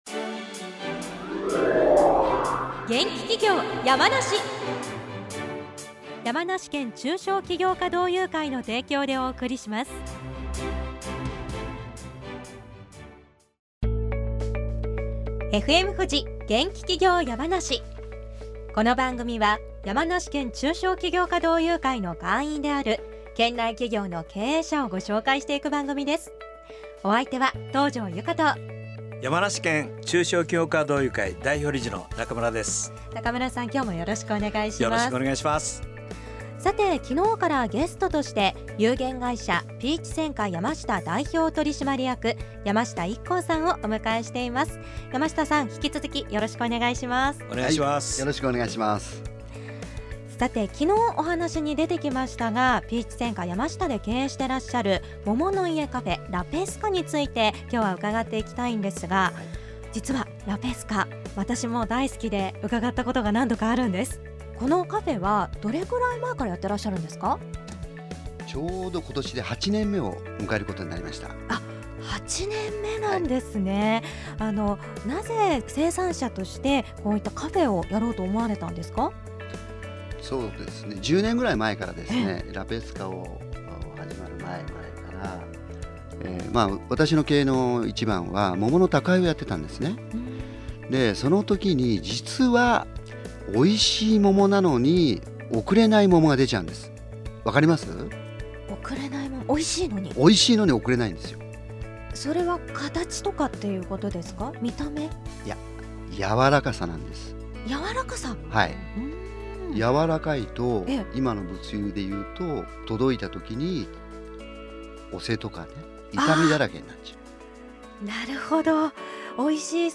7月12日(水)放送